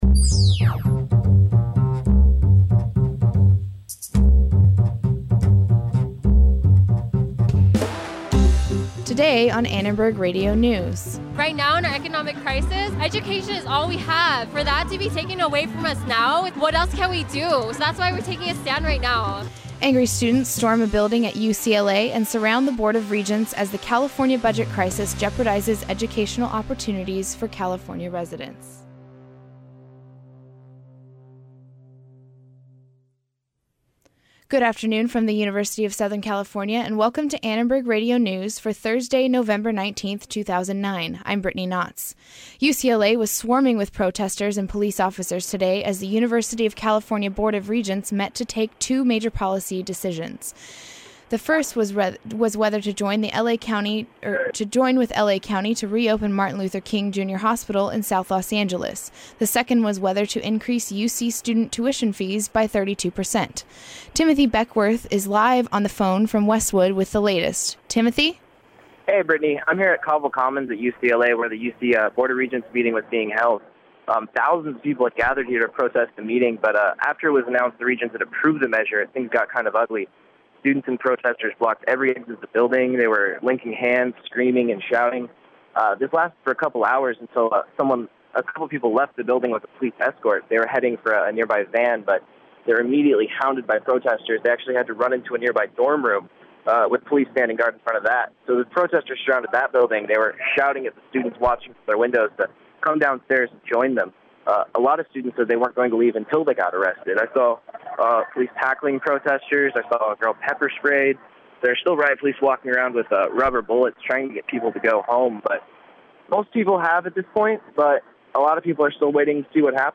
UC students will see a 32 percent increase in tuition rates by the fall of next year - much to the anger of students. We'll hear from students who were protesting the University of California Board of Regents approved proposal.